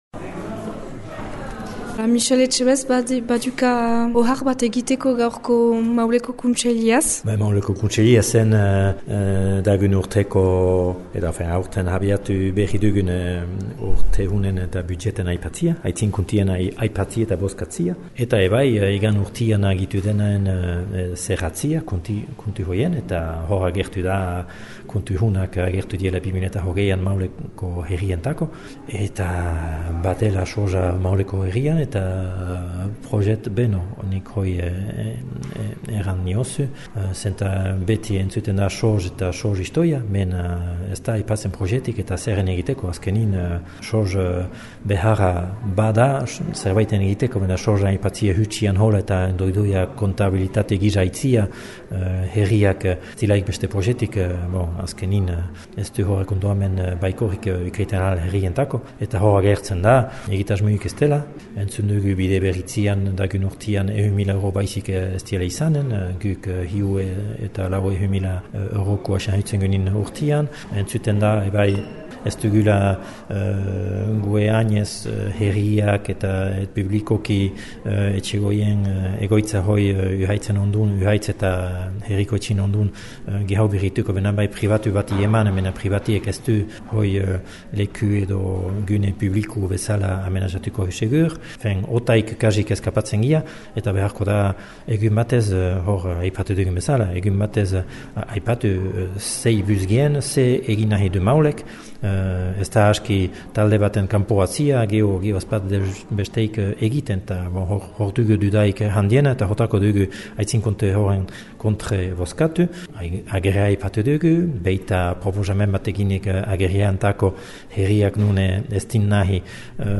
Oposizioko eta gehiengoko haütetsiak entzüngai: